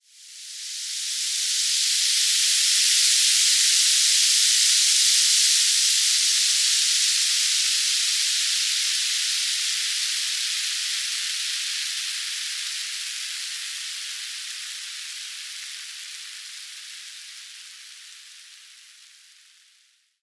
Звуки кислоты
Шипение коррозионной жидкости